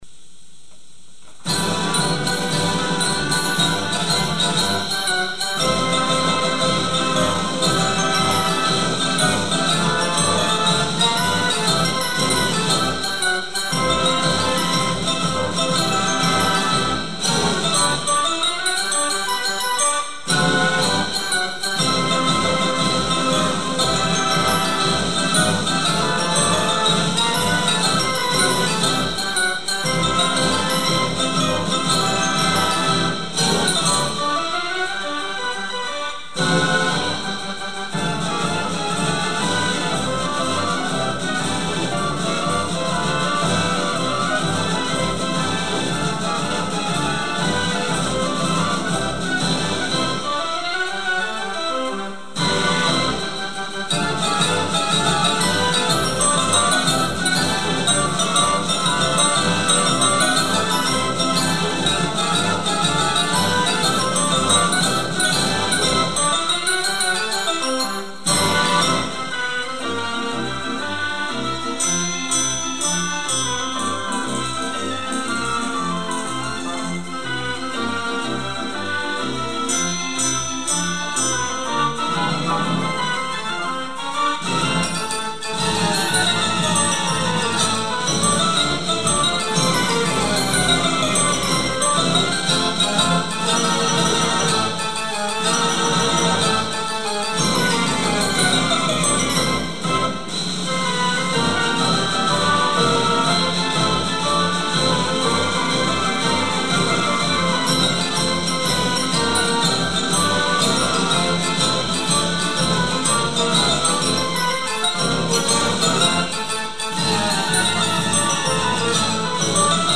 Wurlitzer 165 band organ sound files
Bombasto, by O. R. Farrar (1895). A march, missing from roll 6530 (Nov. 1921), though believed to still be in someone's roll collection as a fragment.
Recorded from the Seabreeze Verbeeck 165.